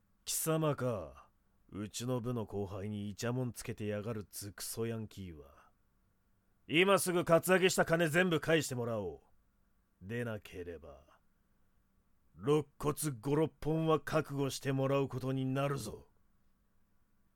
④ 正義の漢
正義の漢.mp3